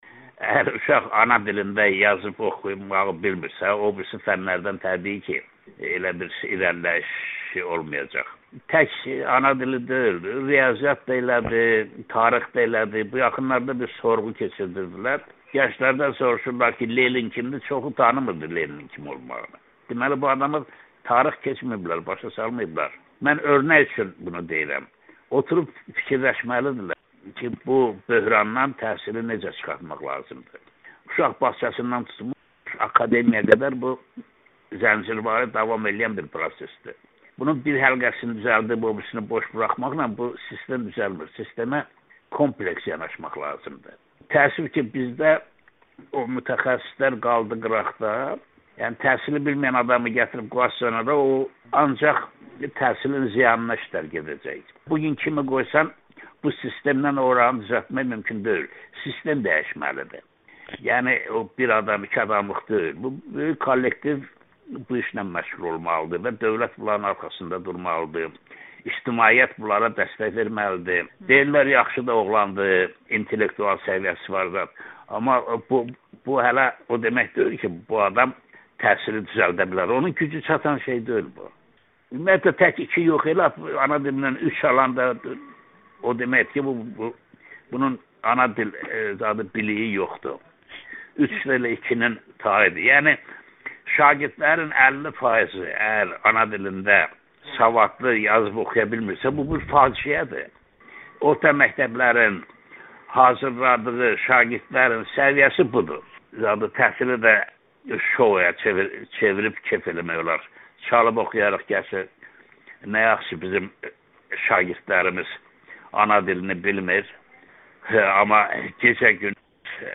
Firudin Cəlilovla müsahibə